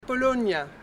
uitspraak Paulownia.